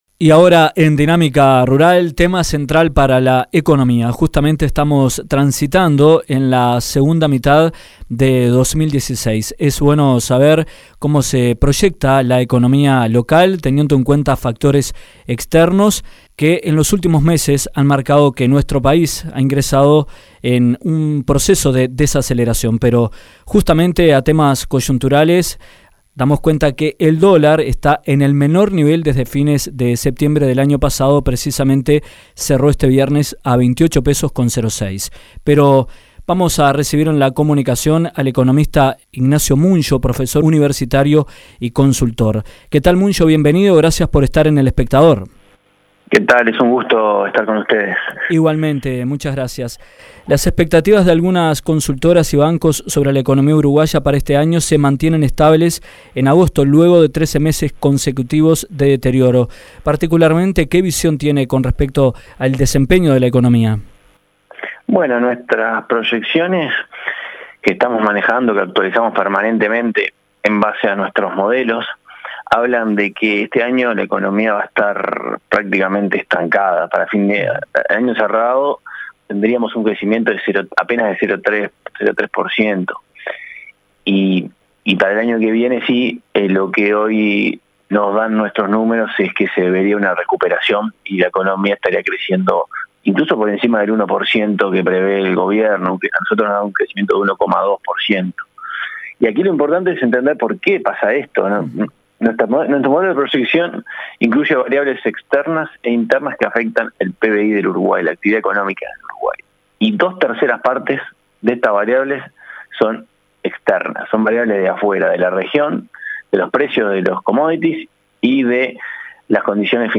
Las expectativas de algunas consultoras y bancos sobre la economía uruguaya para este año se mantienen estables en agosto, luego de más de un año de deterioro. En entrevista con Dinámica Rural